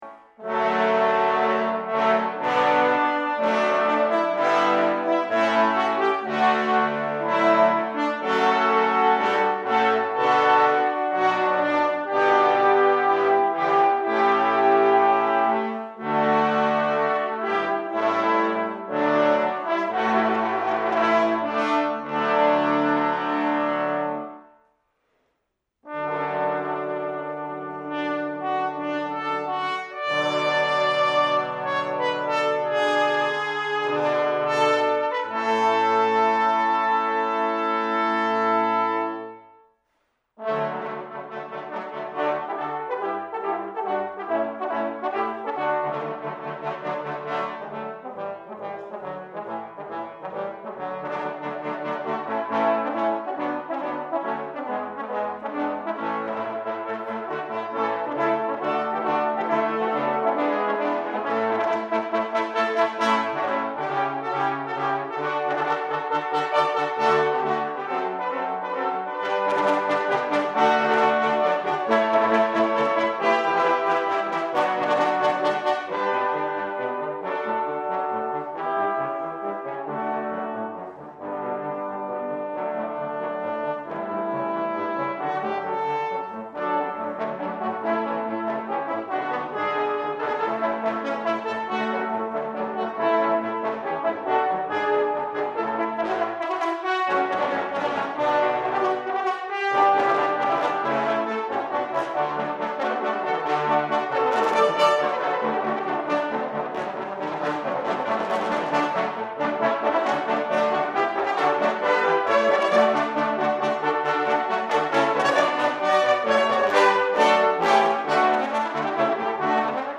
for Trombone Octet